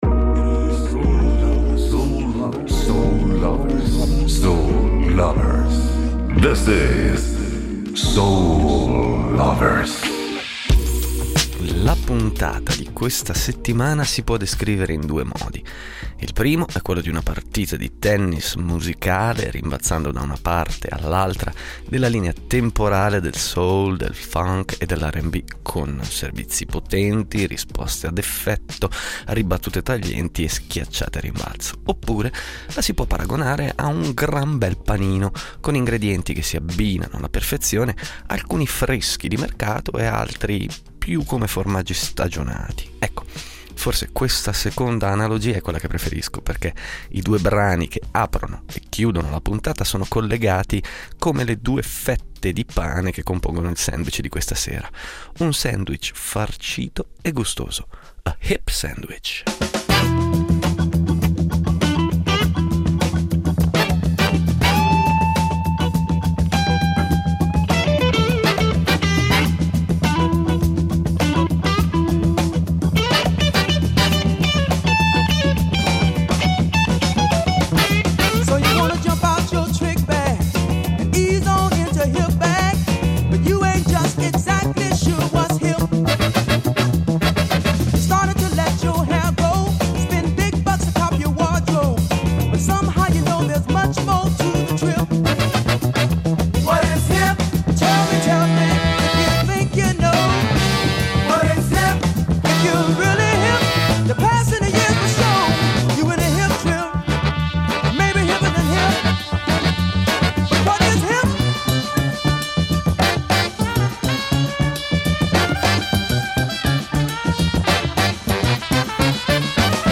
Il primo è quello di una partita di tennis musicale, rimbalzando da una parte all’altra della linea temporale del soul, del funk e dell’rnb, con servizi potenti, risposte ad effetto, ribattute taglienti e schiacciate a rimbalzo.